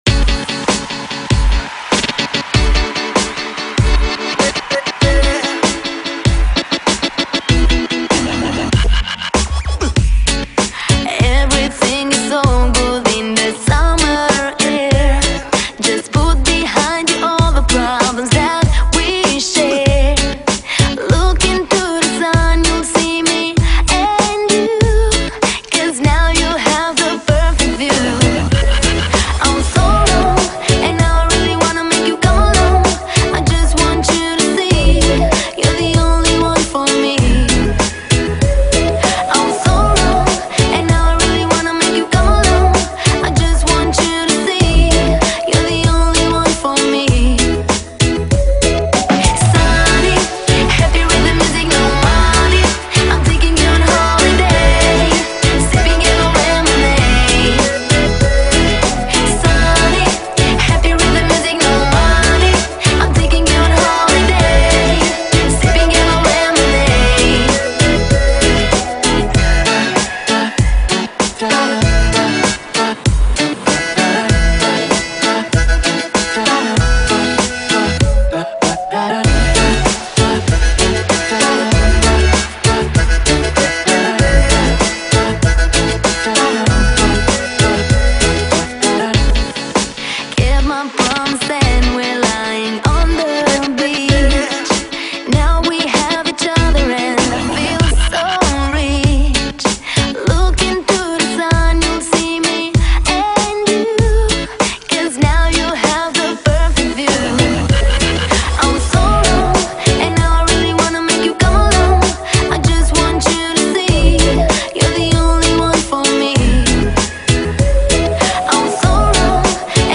Pop, Dance, House